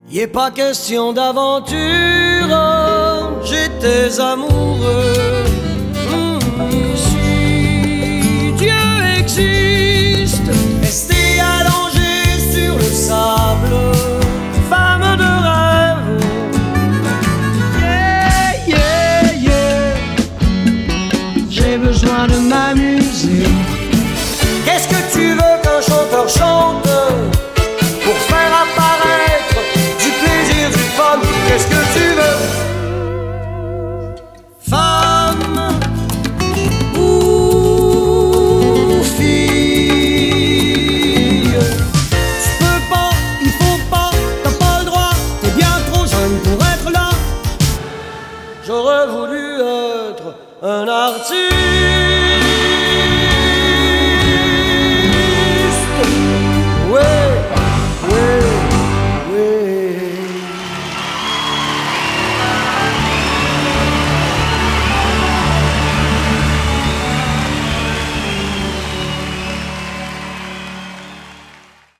MONTAGE AUDIO